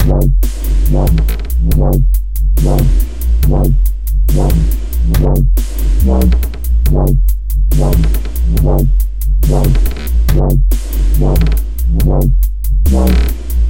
Example Chill Step Groove
描述：140 BPM. Chill step.
标签： 140 bpm Dubstep Loops Groove Loops 4.76 MB wav Key : Unknown
声道立体声